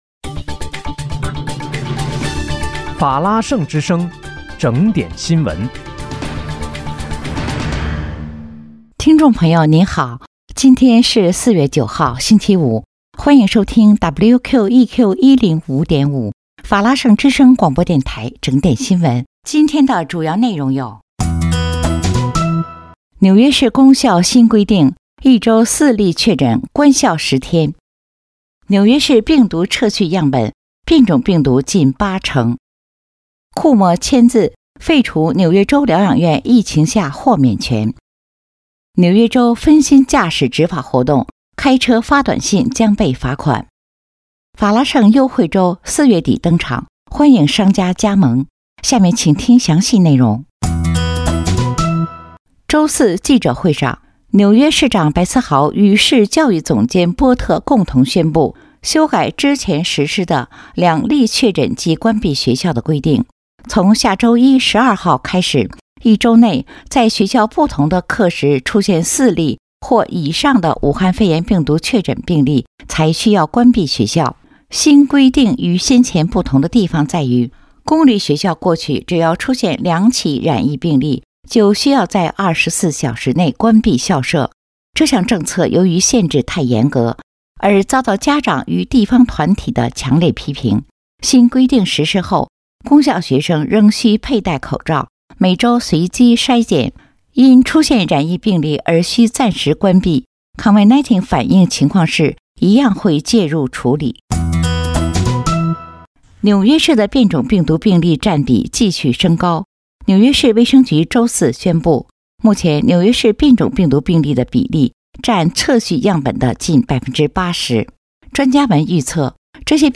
4月9日（星期五）纽约整点新闻